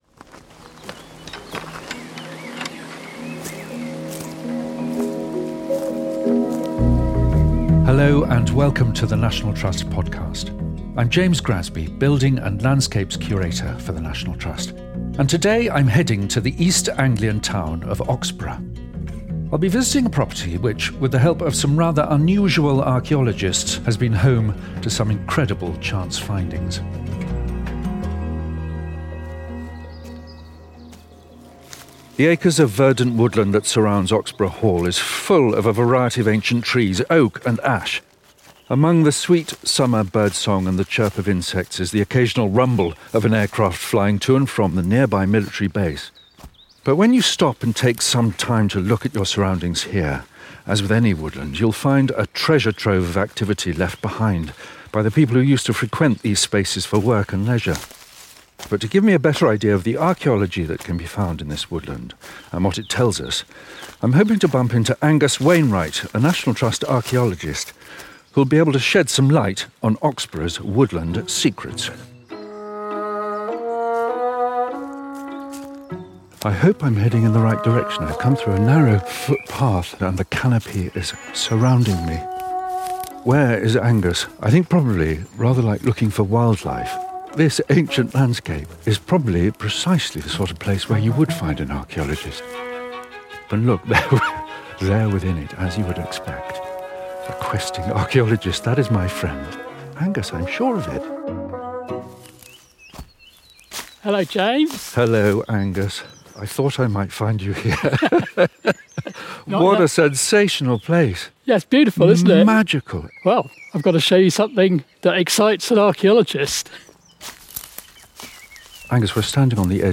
Among the music featured is a performance of early Tudor Choral song